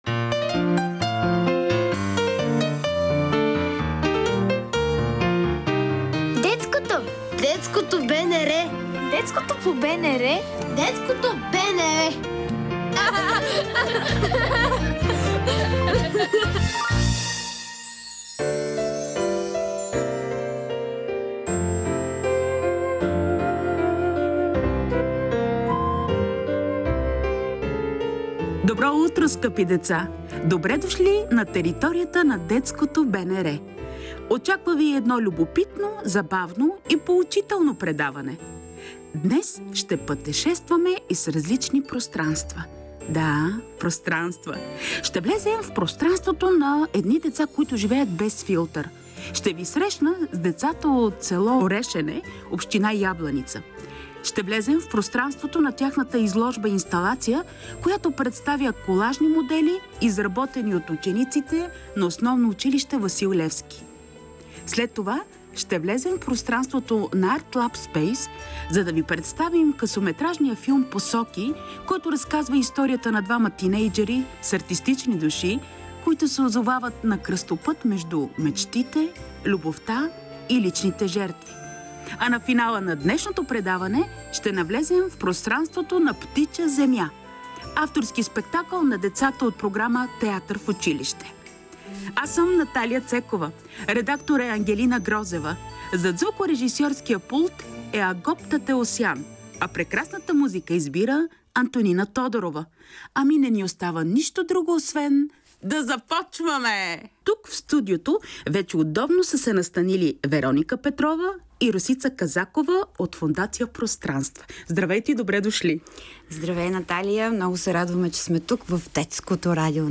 Те са в студиото на Детското.БНР, за да разиграят откъс от пиесата, а от тях ще разберем и как сами са измислили своите герои.